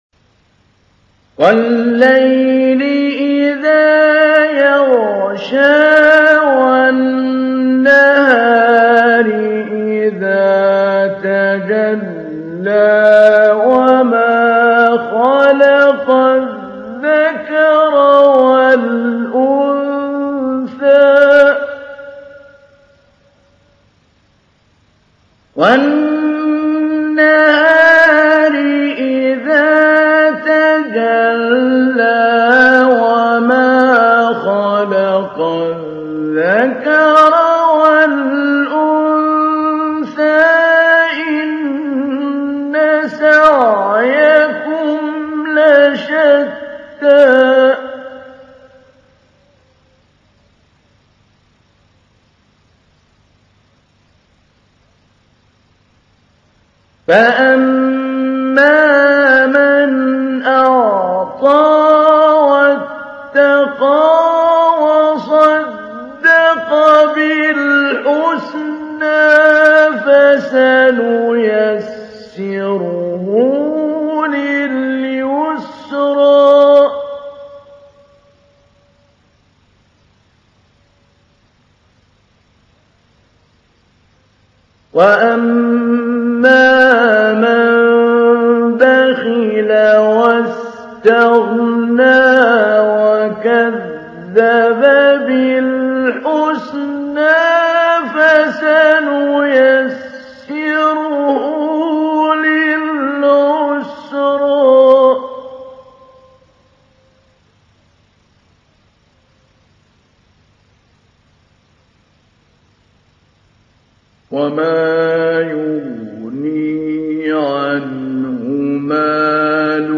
تحميل : 92. سورة الليل / القارئ محمود علي البنا / القرآن الكريم / موقع يا حسين